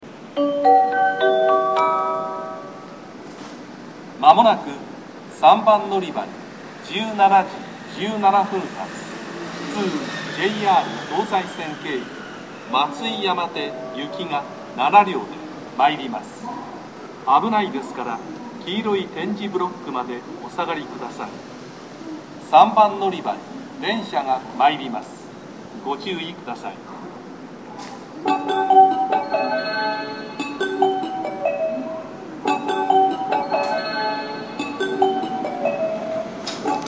この駅では接近放送が設置されています。
スピーカーの数は多めで位置も低めなので収録がしやすいです。
接近放送普通　JR東西線経由　松井山手行き接近放送です。